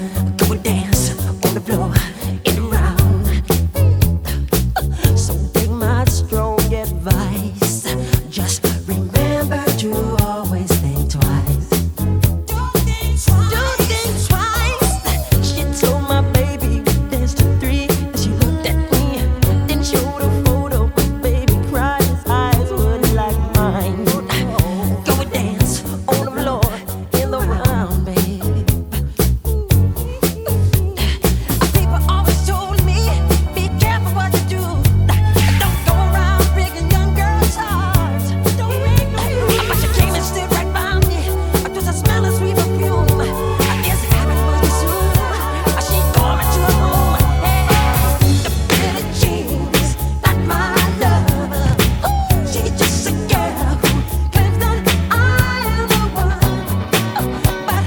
صدای احساسی او و کلید فرعی آهنگ به حال و هوای کلی می افزاید.